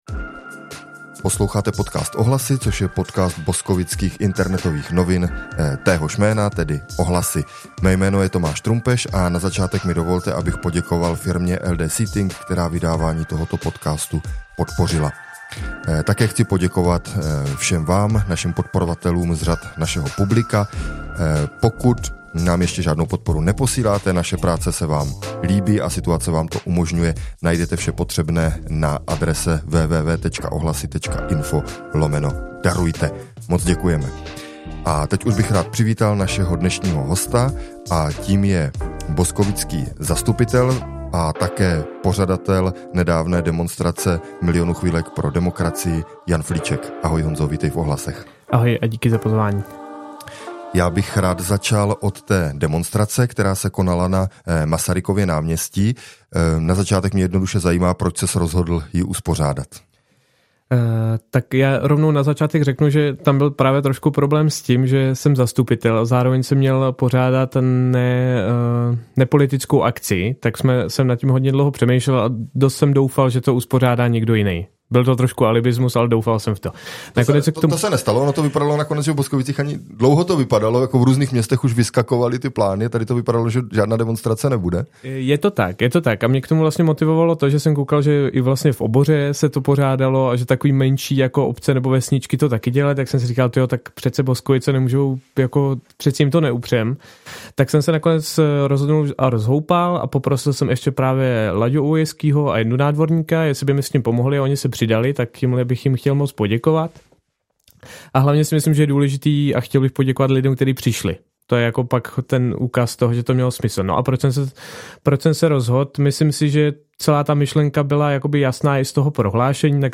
Rozhovor s Janem Flíčkem, boskovickým zastupitelem zvoleným za Změnu22, ze které však před časem vystoupil.